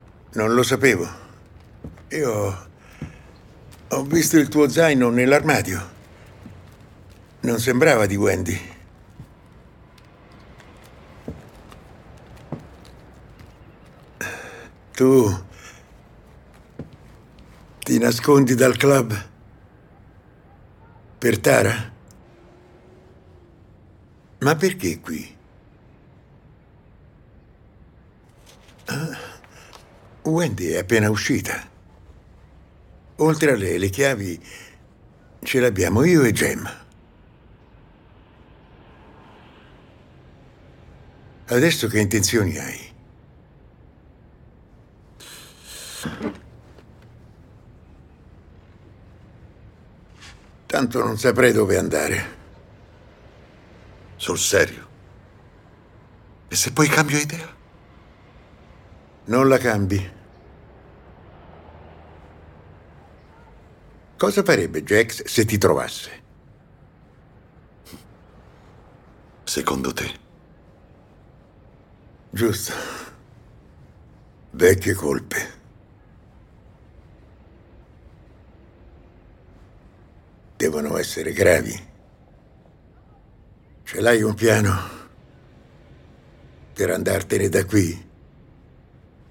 nel telefilm "Sons of Anarchy", in cui doppia Dayton Callie.